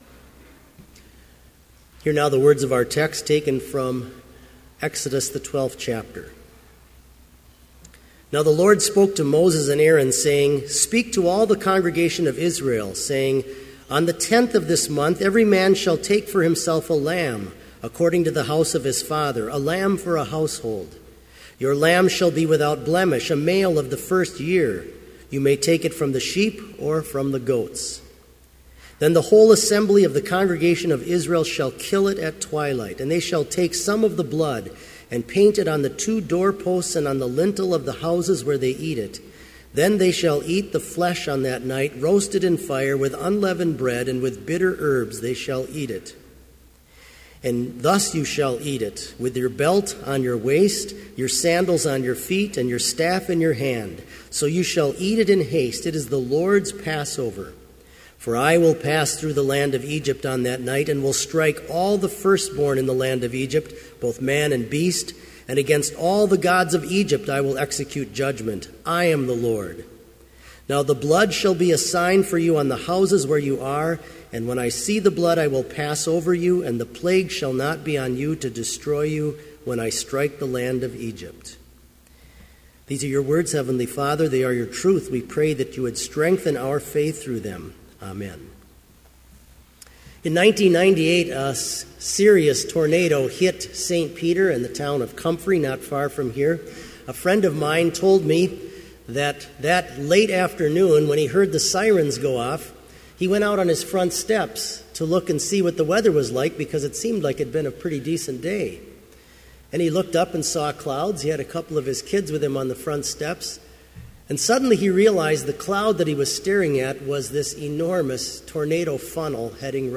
Sermon Only
This Chapel Service was held in Trinity Chapel at Bethany Lutheran College on Friday, February 20, 2015, at 10 a.m. Page and hymn numbers are from the Evangelical Lutheran Hymnary.